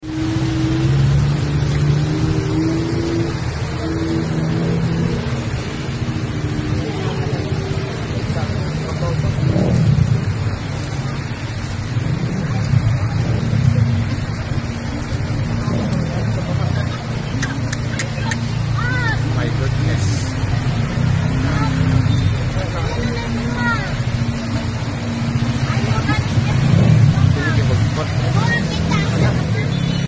WATCH: A downpour floods downtown sound effects free download
WATCH: A downpour floods downtown sound effects free download By gmanews 2 Downloads 8 hours ago 30 seconds gmanews Sound Effects About WATCH: A downpour floods downtown Mp3 Sound Effect WATCH: A downpour floods downtown Cebu City evening on Saturday, August 30, 2025.